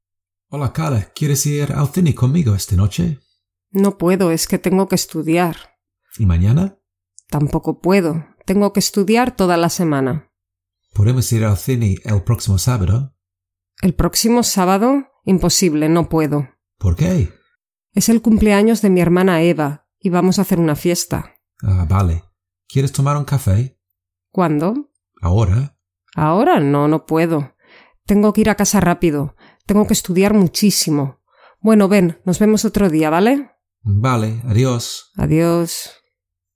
4.-Listening-Practice-Poder-Can-Part-1.mp3